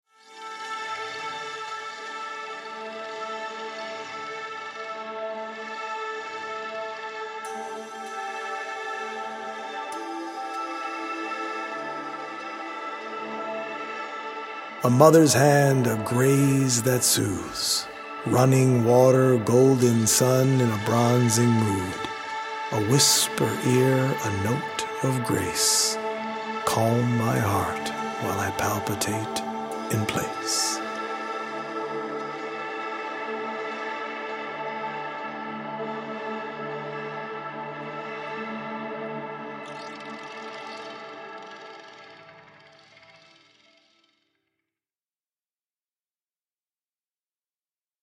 healing audio-visual poetic journey
healing Solfeggio frequency music
EDM producer